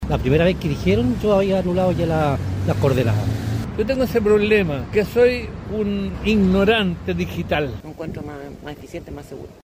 Consultados por La Radio esta semana, transeúntes señalan que “la primera vez que dijeron -que se acabaría la tarjeta- yo había anulado” el plástico.